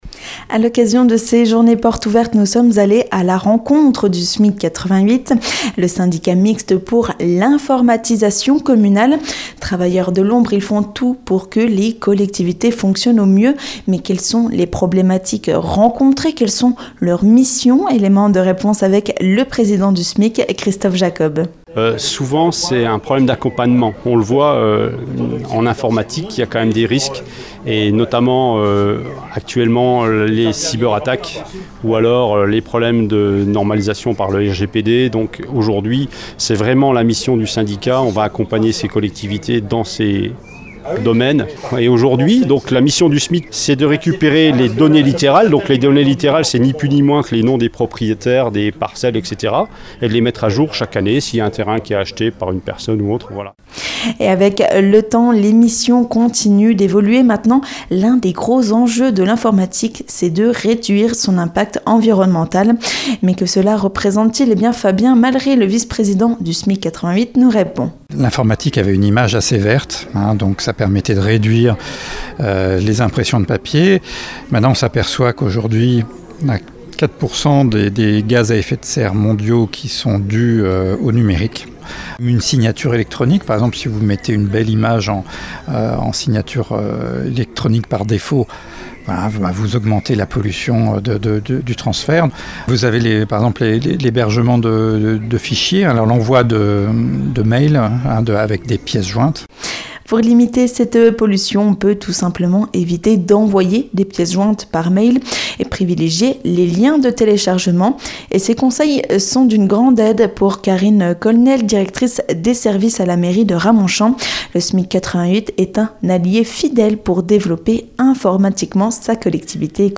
A l'occasion des portes ouvertes du SMIC 88, le Syndicat Mixte pour l'Informatisation Communale, nous sommes allés à la rencontre de ses agents ! Des travailleurs de l'ombre sans qui les collectivités ne seraient pas aussi performantes. Un reportage à écouter juste ici pour découvrir le SMIC des Vosges !